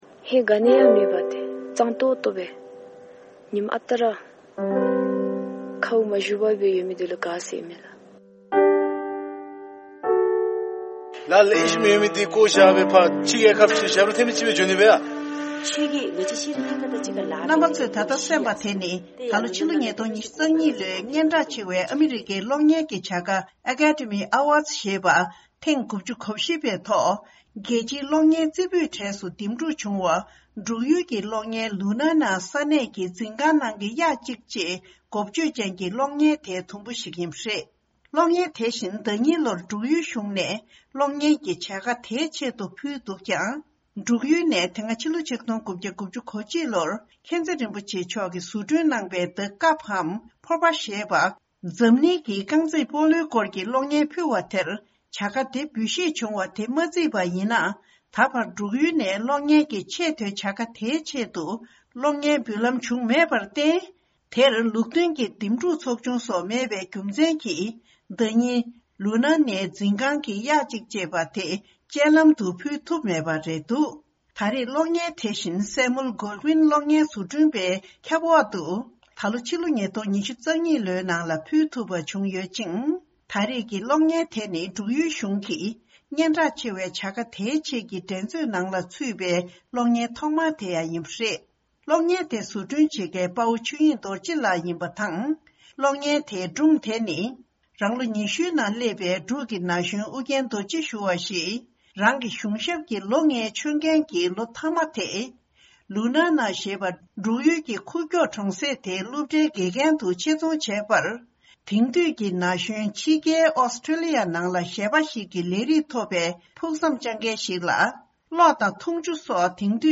ཕྱོགས་བསྒྲིགས་དང་སྙན་སྒྲོན་ཞུ་ཡི་རེད།།